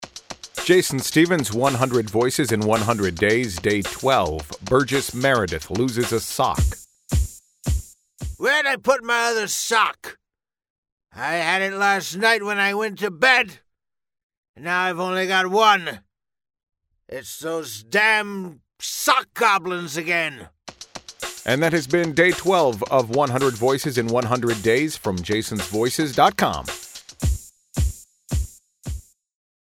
For day twelve of the 100 Voices in 100 Days project, I went with my Burgess Meredith impression.
Tags: 100 Voices 100 Days, Burgess Meredith impression